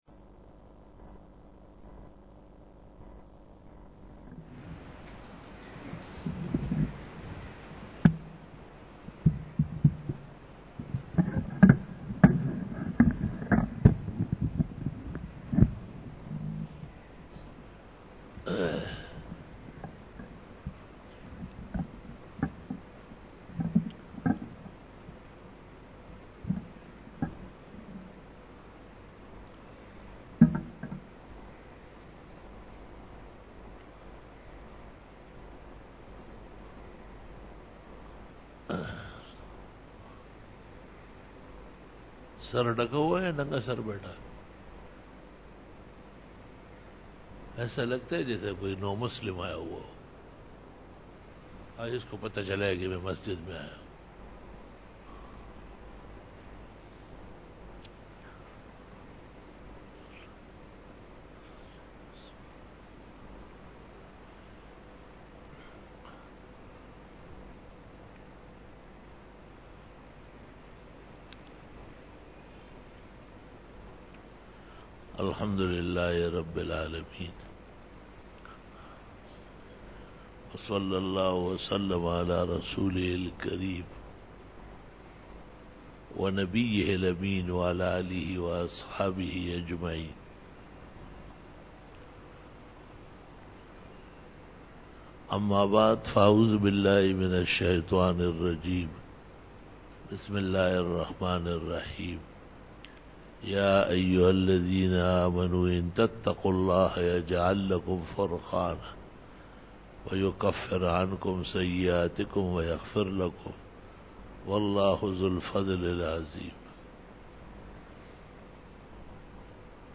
12 BAYAN E JUMA TUL MUBARAK (23 MARCH 2018) (05 Rajab 1439H)